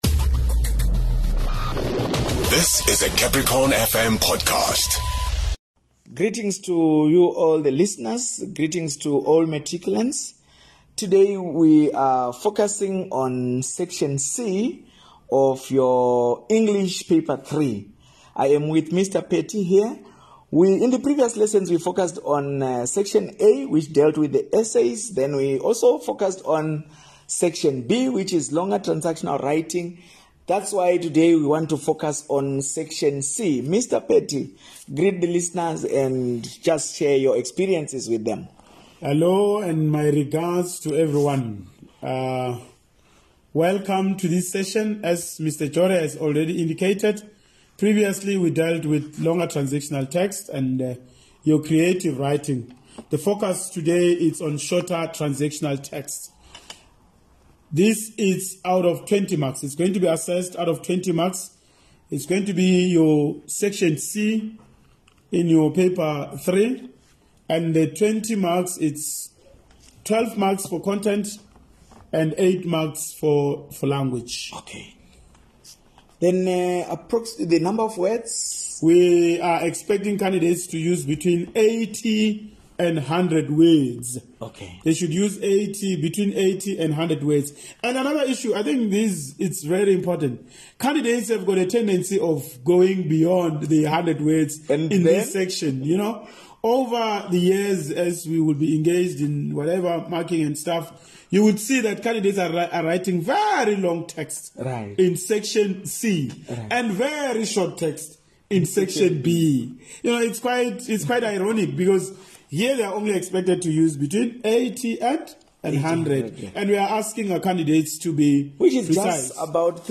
Grade 12 Lessons